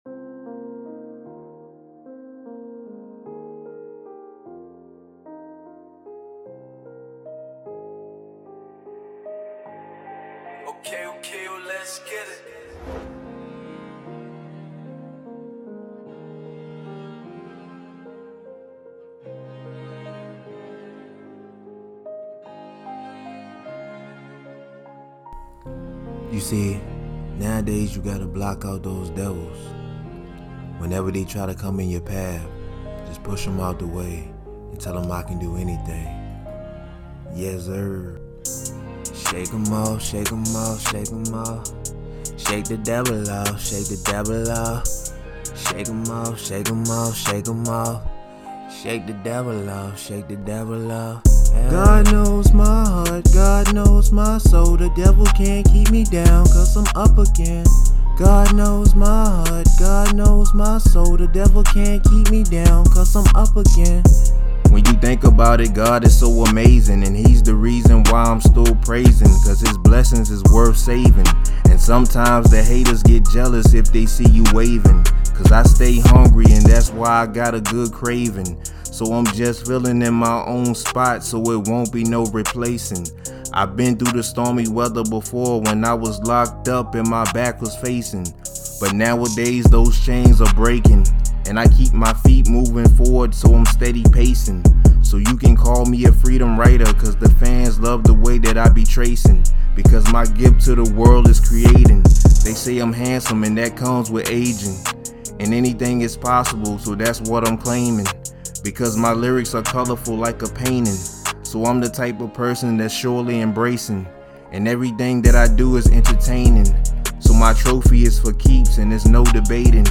Hiphop
New inspirational song